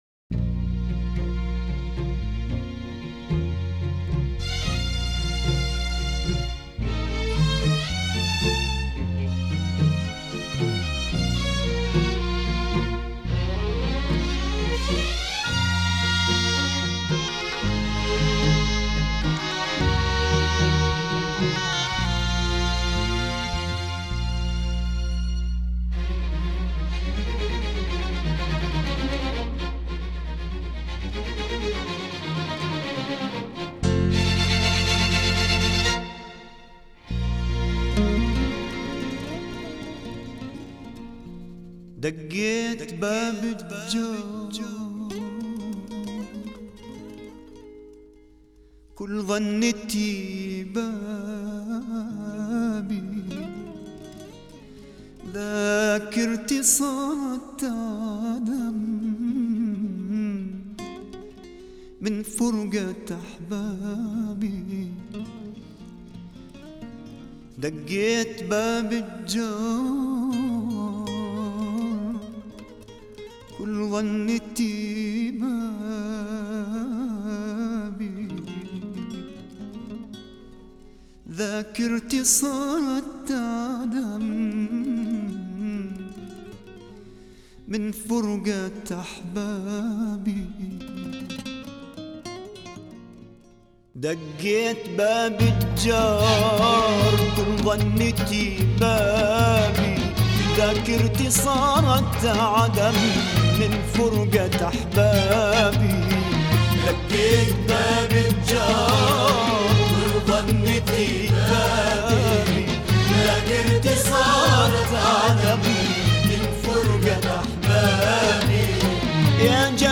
سبک موسیقی عربی و پاپ